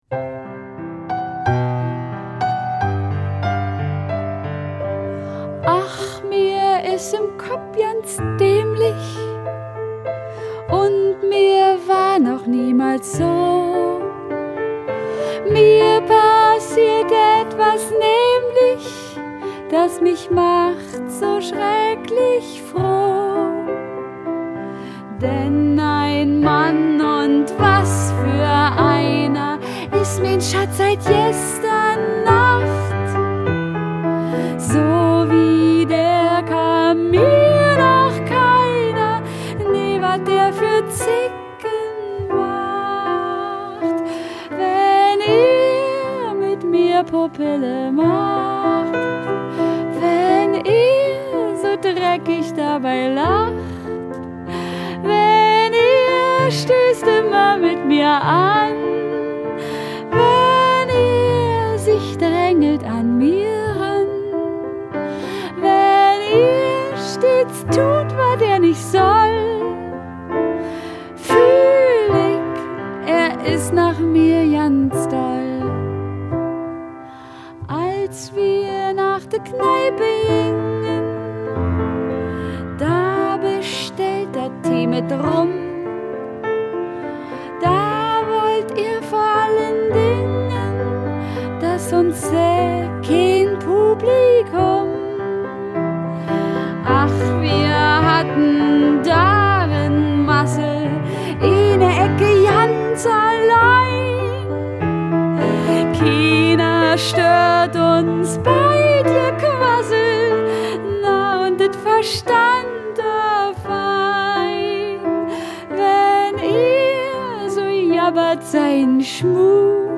Chanson:
Gesang und Klavier